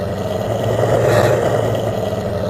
ghoul.ogg